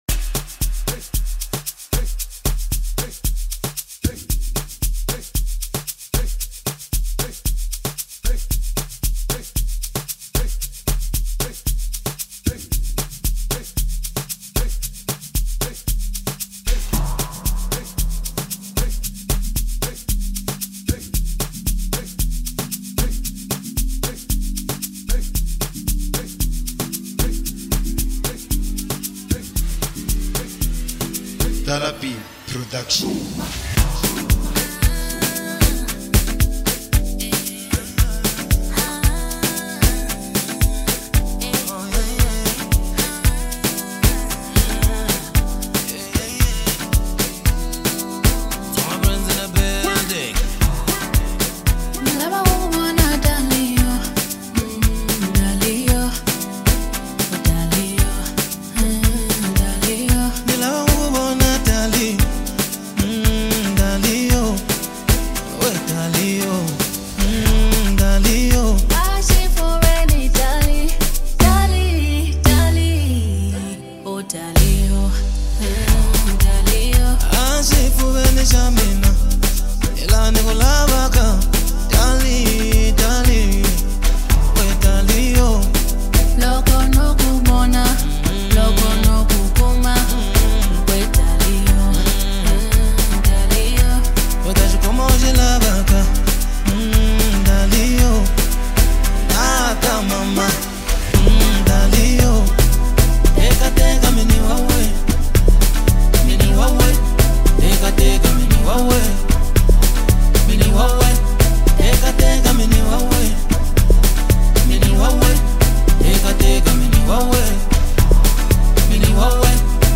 a captivating blend of soulful melodies and vibrant rhythms